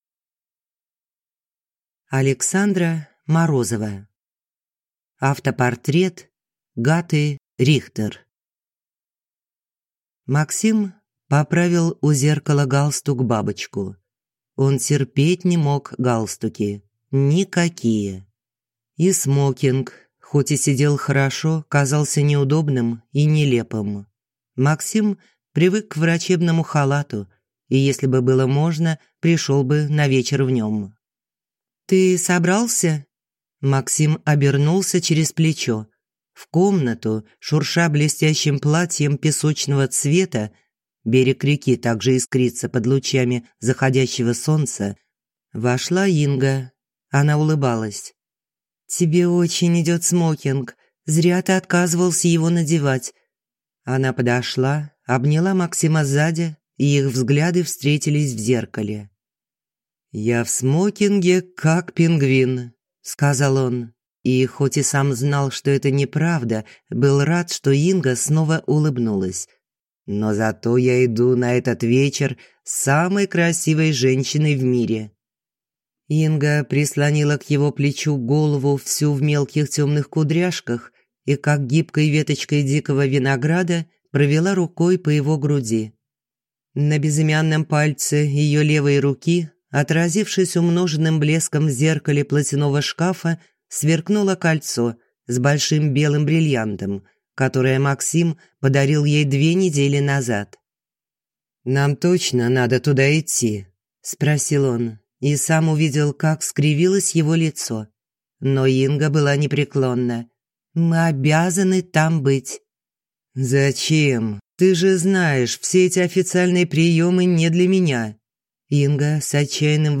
Аудиокнига Автопортрет Гаты Рихтер | Библиотека аудиокниг
Прослушать и бесплатно скачать фрагмент аудиокниги